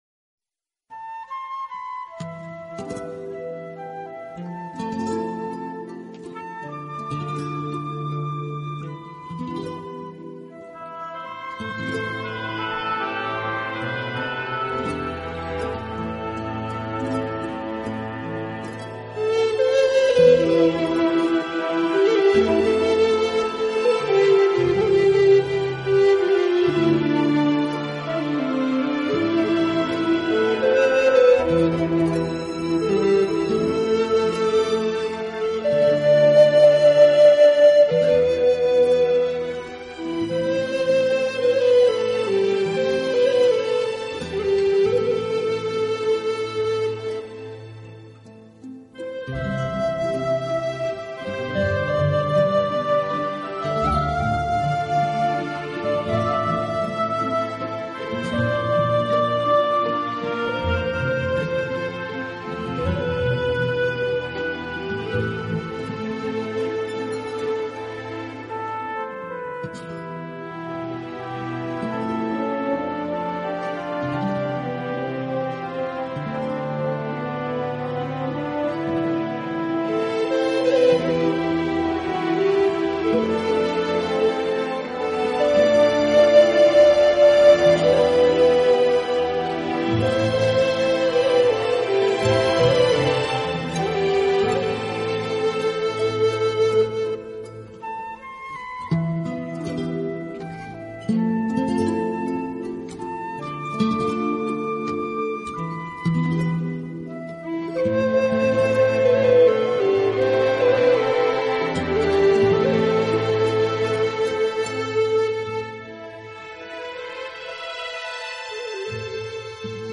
的清晰度，准确的结像力，极宽的动态范围，更逼真的现场效果，更细腻纯正的音
竹笛：中国横吹管乐器，竹制，上开有只孔和膜孔各一，按指孔六个，吹奏
对气时激起笛膜振动，发出特有的清脆的音色，常用之笛有两种，用于伴奏